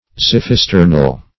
-- Xiph"i*ster"nal a. [1913 Webster]
xiphisternal.mp3